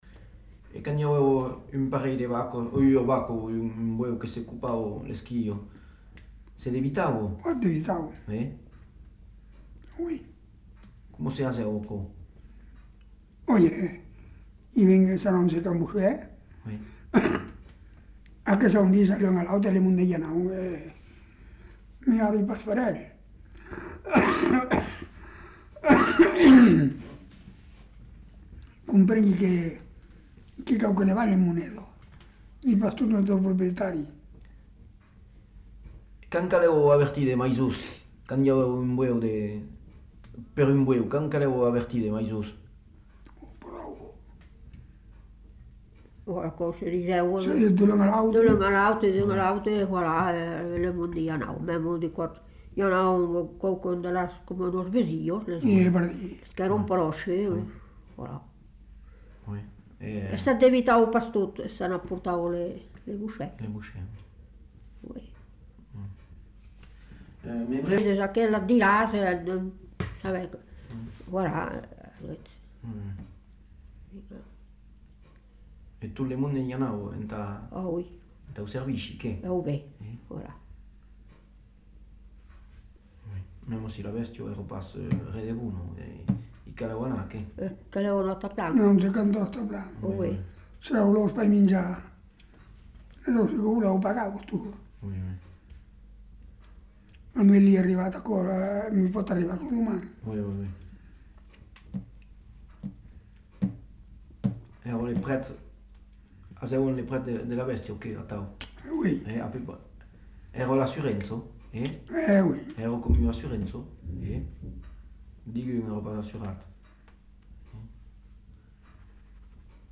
Aire culturelle : Savès
Lieu : Montadet
Genre : témoignage thématique